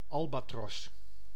Ääntäminen
France: IPA: [al.ba.tʁos]